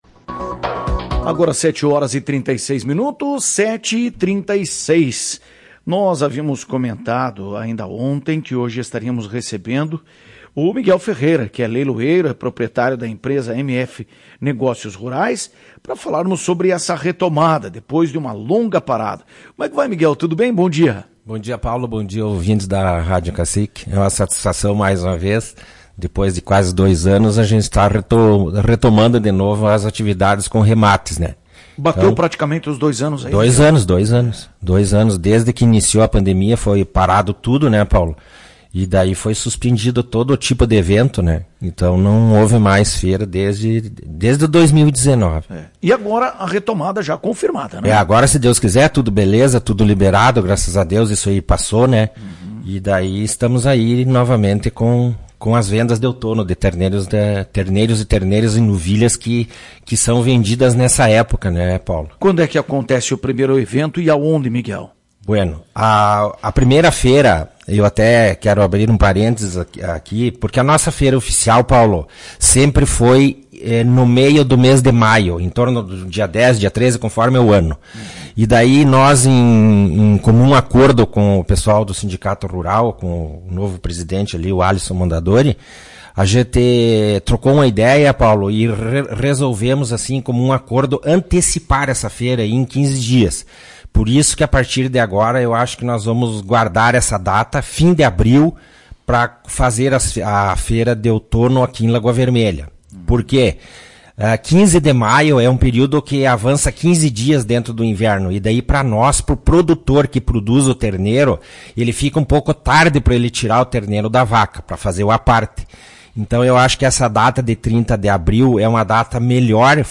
Em entrevista à Tua Rádio Cacique